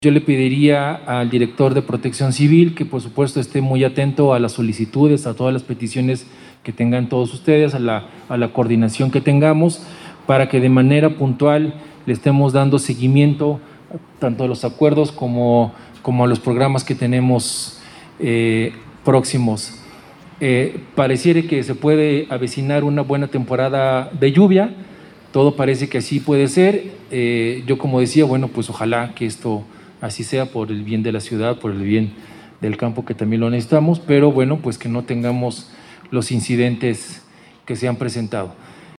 AudioBoletines
Ricardo Benavides Hernández – Secretario de Seguridad Ciudadana